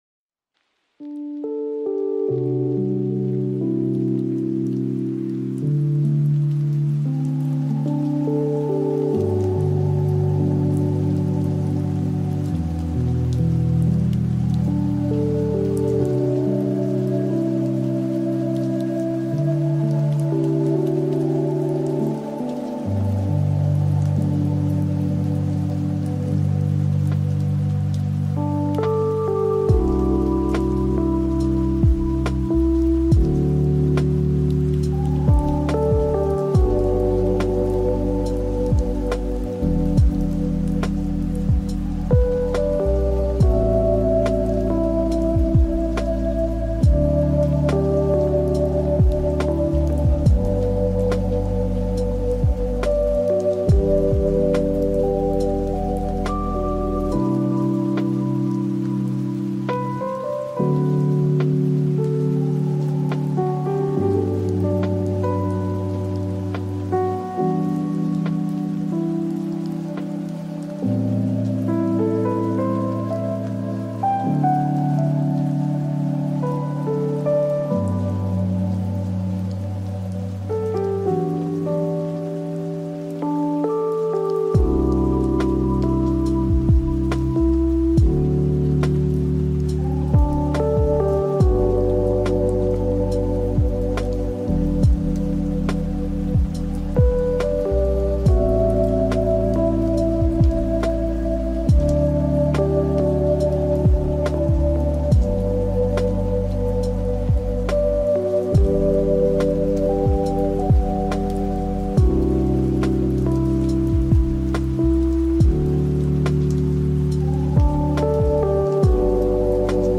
☂ Quiet Rain Loops — Minimal Ambience to Stay Grounded
Each episode of LoFi Sounds is carefully crafted to blend mellow hip-hop beats with atmospheric textures, gentle rhythms, and warm vinyl crackles.